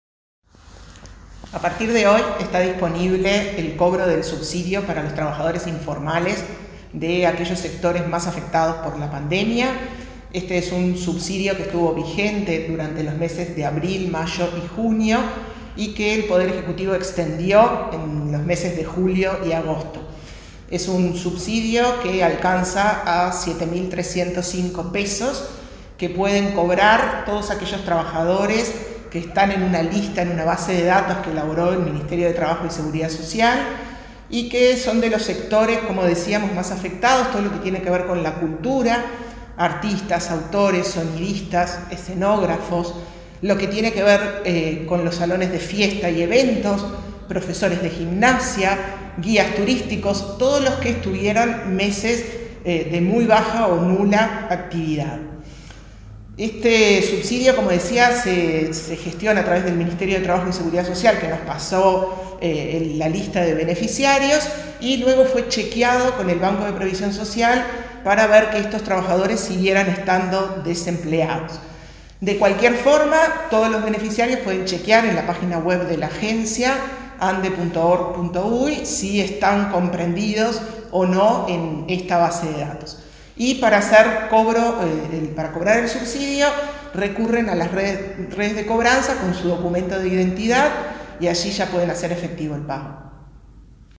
Entrevista a la presidenta de la Agencia Nacional de Desarrollo (ANDE), Carmen Sánchez